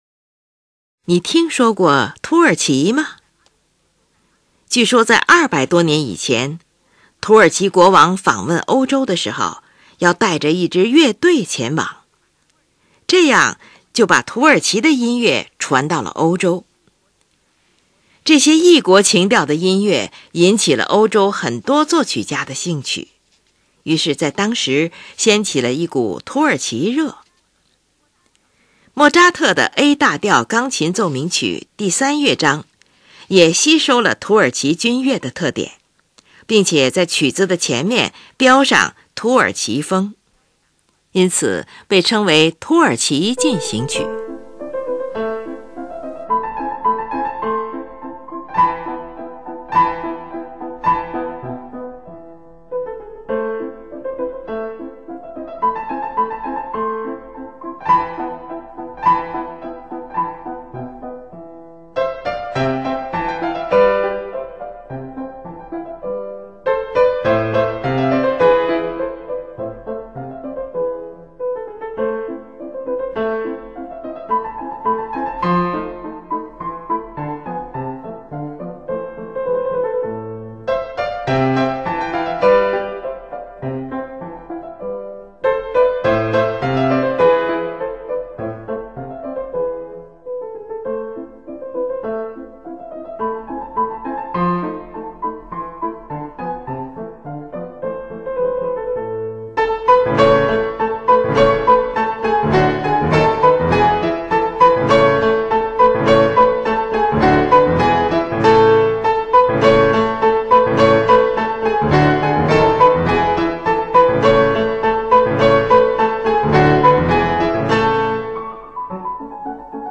最先出现的是B主题，它的旋律轻快活泼而精巧，用了大量的十六分音符的节奏型。
这是一支旋律雄伟而具有英雄气质的进行曲，E音在强拍上得到了充分的强调，可以说，曲调就是围绕着它发展而成的。
第二插部C，旋律轻盈优美、清新流畅，并且起伏连绵不断，与主部构成了鲜明的对比。